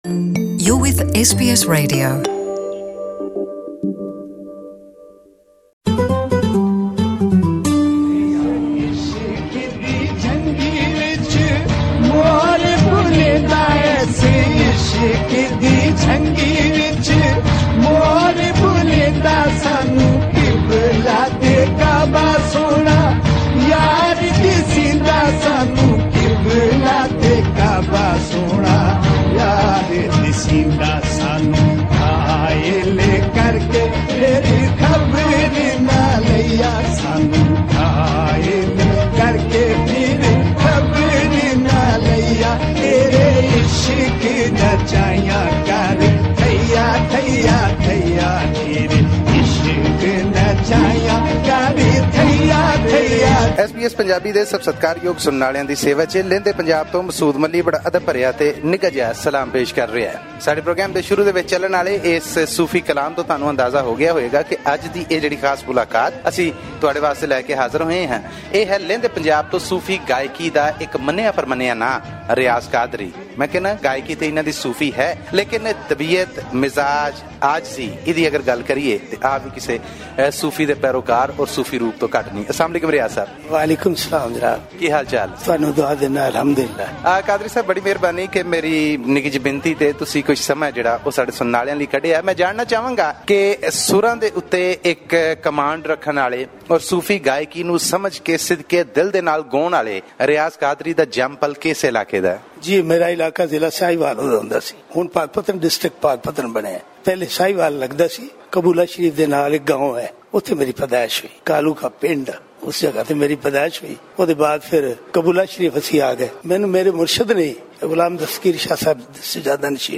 This week, our Lahore-based correspondent interviews Riaz Qadri, an exponent of Sufi music whose popularity isn't limited only to Pakistan but also spills across the Indian Subcontinent.